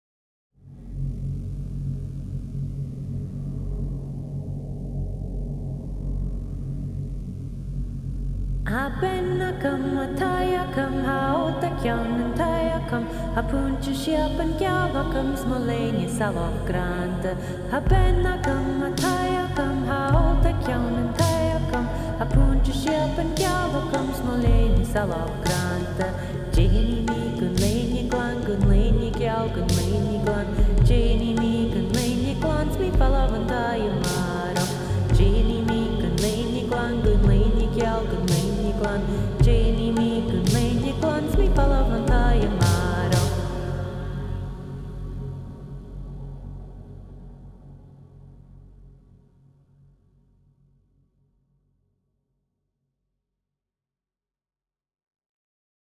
Tha Bean Agam Player Traditional play stop mute max volume repeat Tha Bean Agam Gaelic Music Download Tha Bean Agam MP3